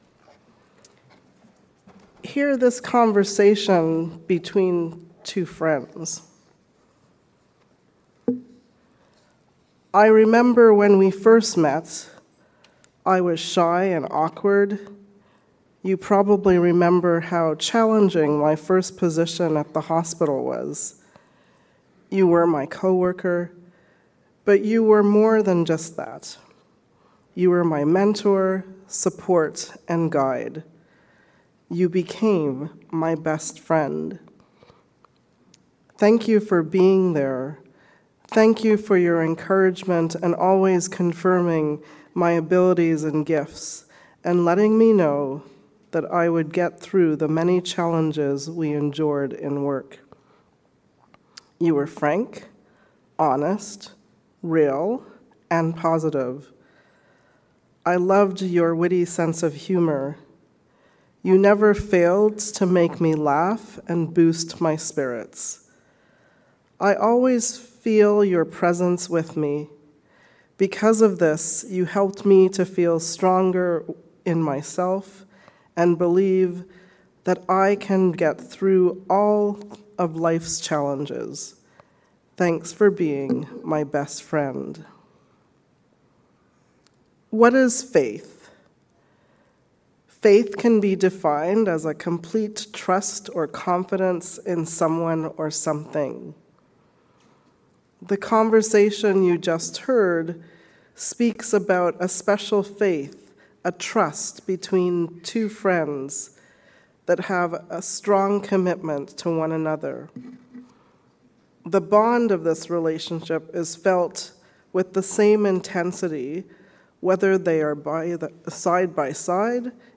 Sacred Friendship. A sermon on John 15:9-17